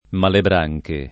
vai all'elenco alfabetico delle voci ingrandisci il carattere 100% rimpicciolisci il carattere stampa invia tramite posta elettronica codividi su Facebook Malebranche [ malebr #j ke ] n. pr. m. pl. — i diavoli della bolgia dei barattieri nell’Inferno dantesco